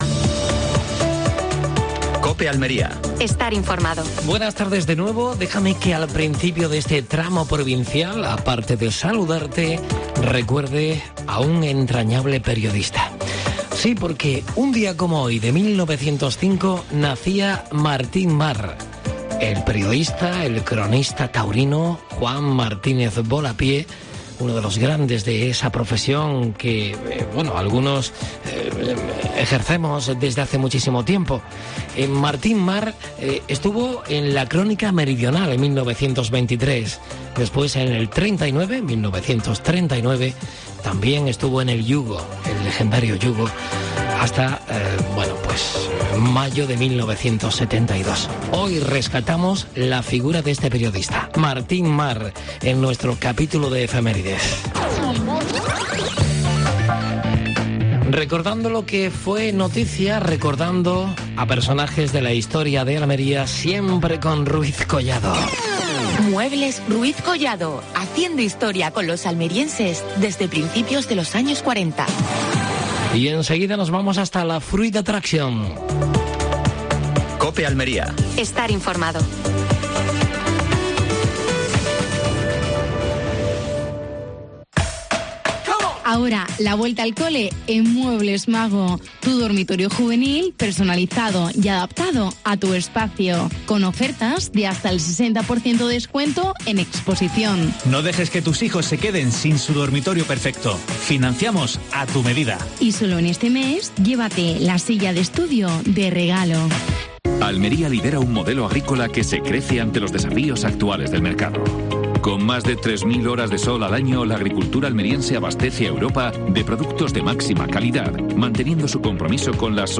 AUDIO: Segunda jornada de Fruit Attraction. Entrevista a Fernando Giménez (diputado provincial). Última hora deportiva.